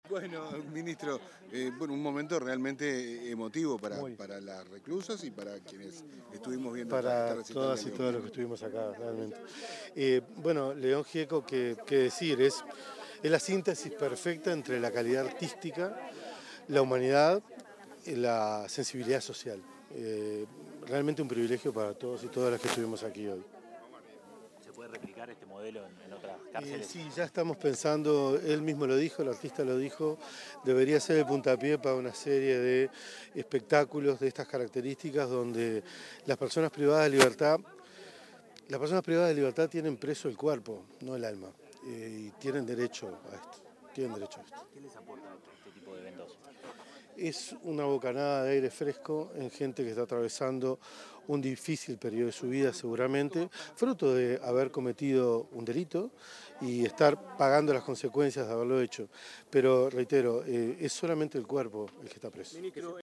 Declaraciones del ministro del Interior, Carlos Negro
Declaraciones del ministro del Interior, Carlos Negro 26/05/2025 Compartir Facebook X Copiar enlace WhatsApp LinkedIn El ministro del Interior, Carlos Negro, dialogó con la prensa, tras participar en una actividad realizada en la Unidad n.° 5 del Instituto Nacional de Rehabilitación (INR).